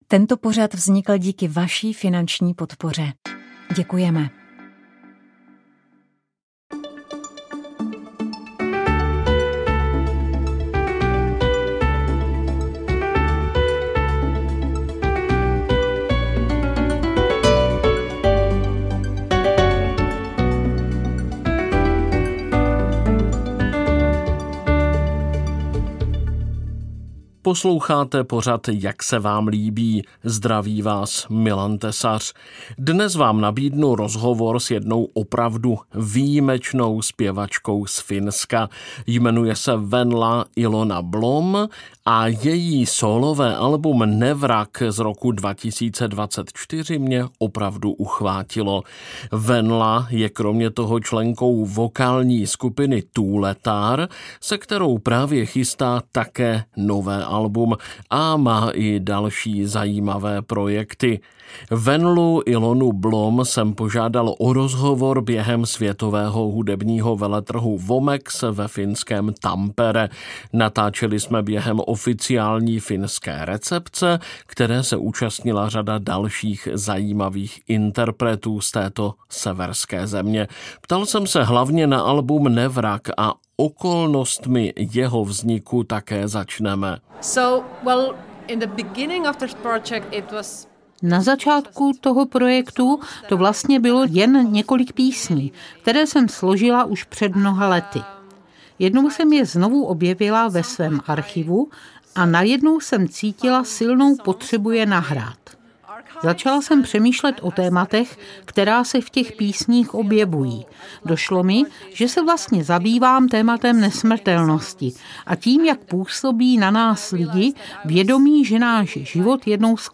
Žamboši – chystáme rozhovor o albu Skrýš